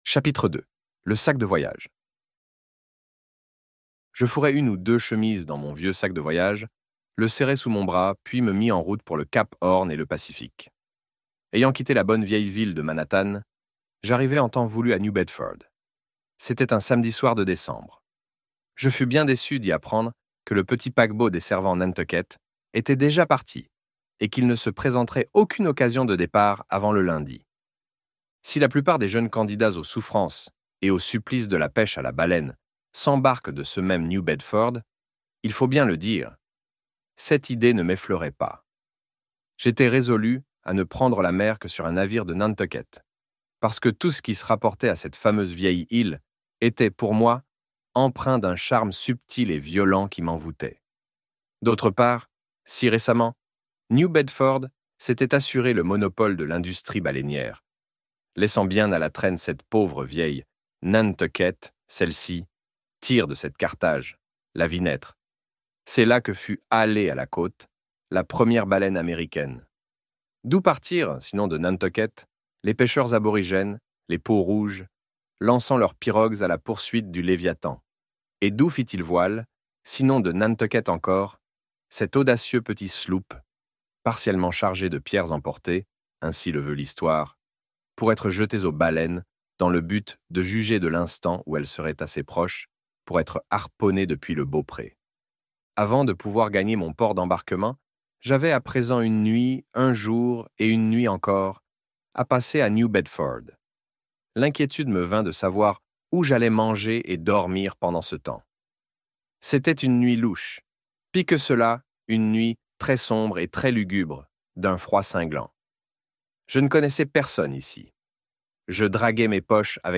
Moby Dick - Livre Audio